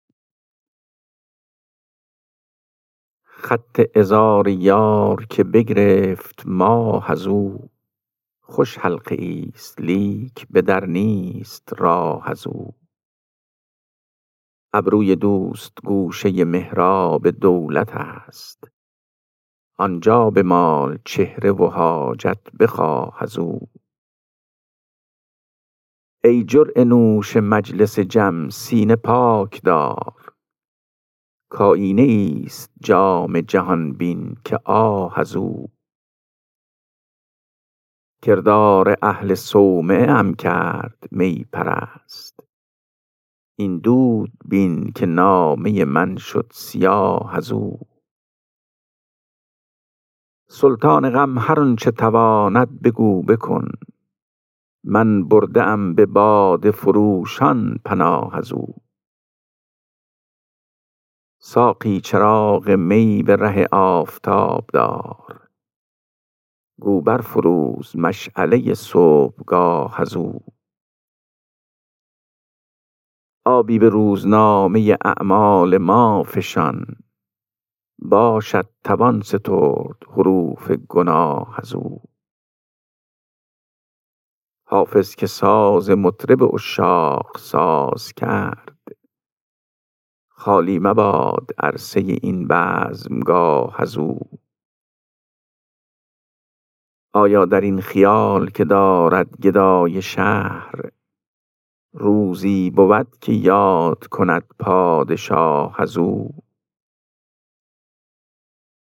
خوانش غزل شماره 413 دیوان حافظ